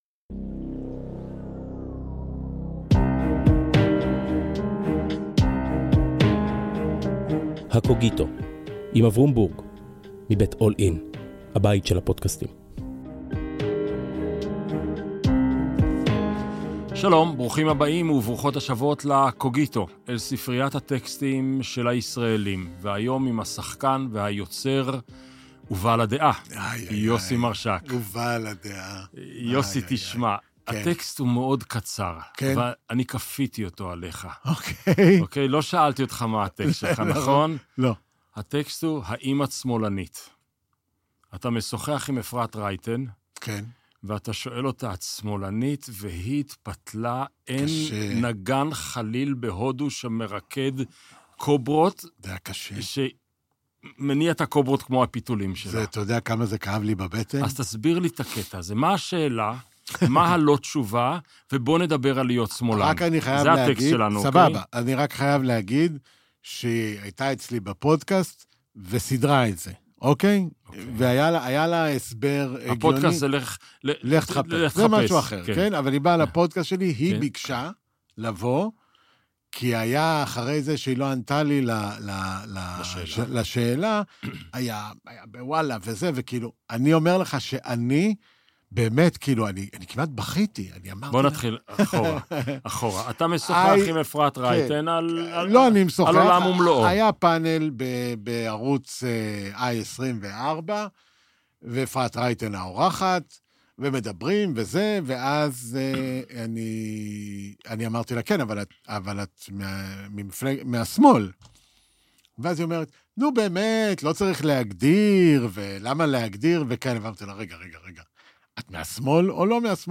לכל איש ואישה יש טקסט, עליו נבנים המון מגדלי חיים. בפודקסט שבועי משוחח אברום בורג עם דמות מובילה אחרת בשדה התרבות והרוח על הטקסט המכונן של חייה. שיחה לא שיפוטית, קשובה אבל מאתגרת.